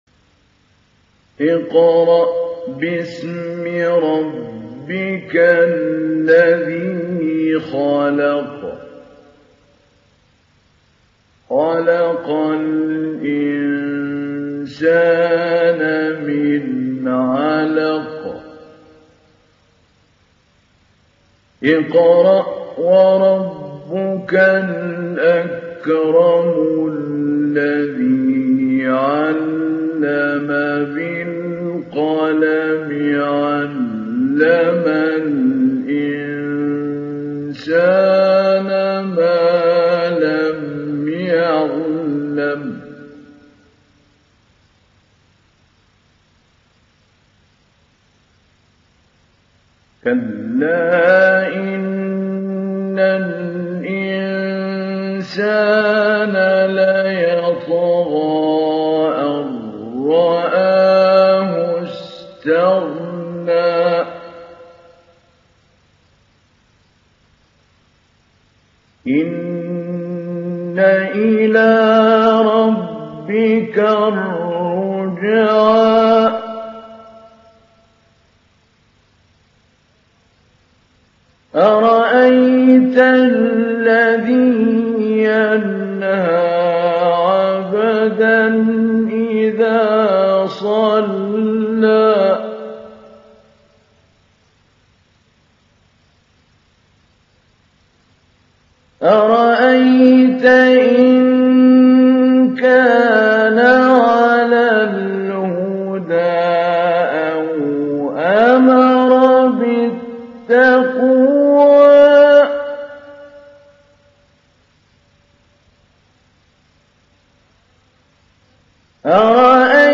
Télécharger Sourate Al Alaq Mahmoud Ali Albanna Mujawwad